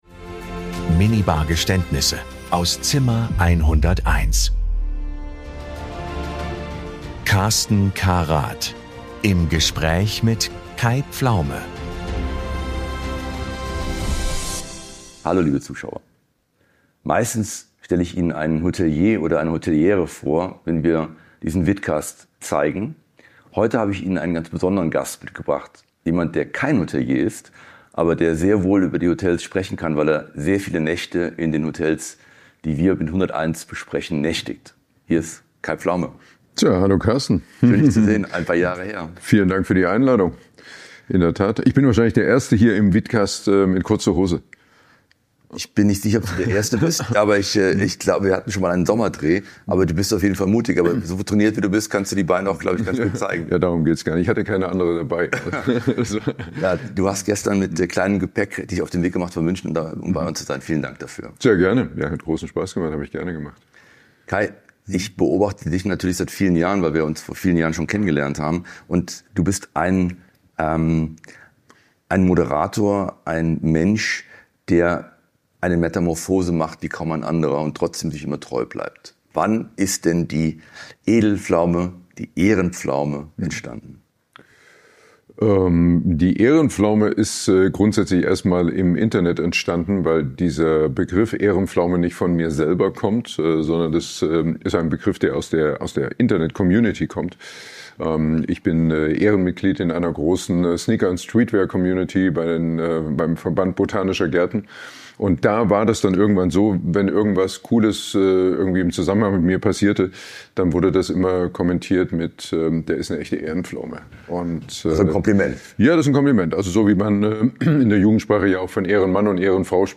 Es wird reflektiert, offen gesprochen und manchmal auch grundlegend hinterfragt.
Diese Woche zu Gast ist Kai Pflaume.